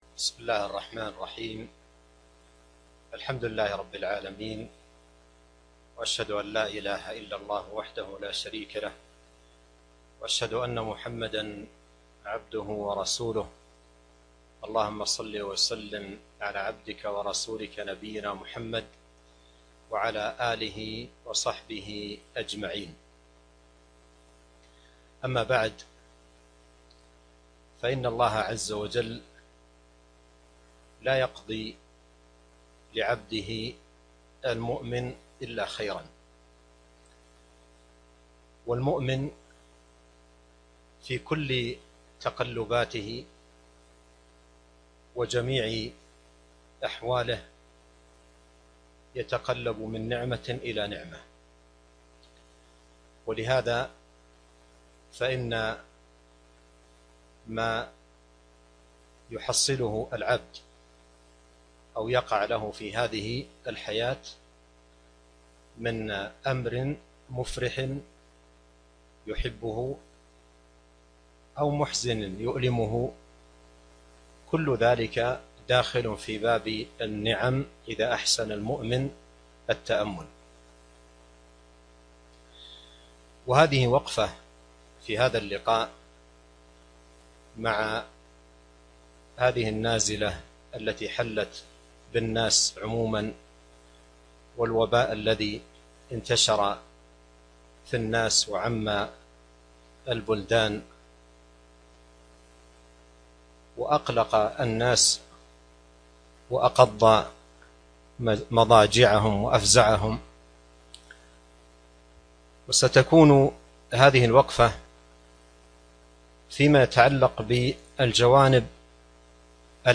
محاضرة قيمة - وقفات ومسائل عقدية مع الجوائح 5 ذي القعدة 1441هــ